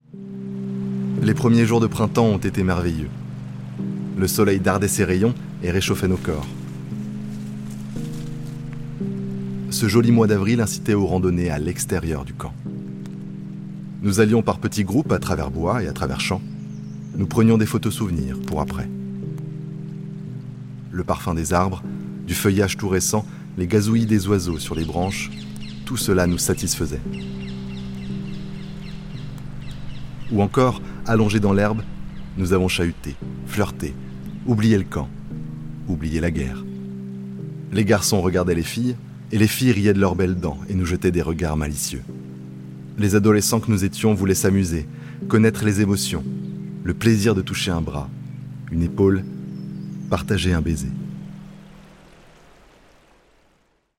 Narration Podcast - Lecture épistolaire 2
- Basse